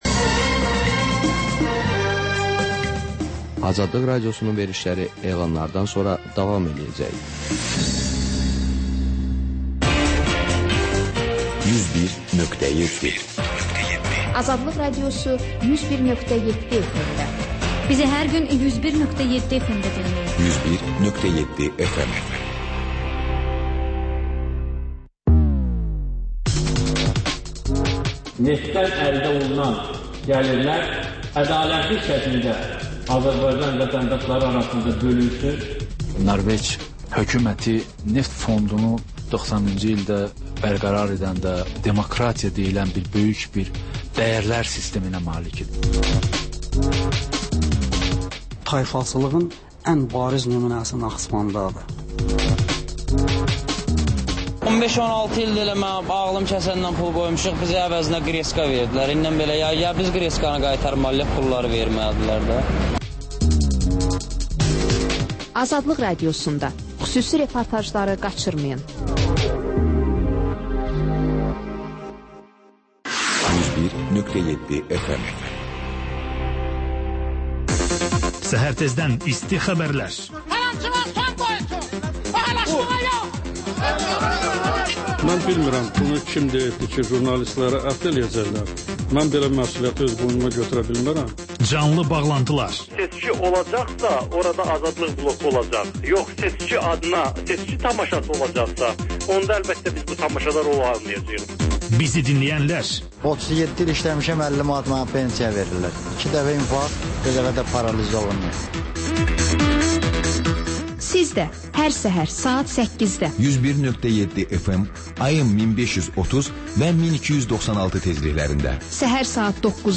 Xəbərlər, sonra CAN BAKI: Bakının ictimai və mədəni yaşamı, düşüncə və əyləncə həyatı…